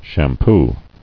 [sham·poo]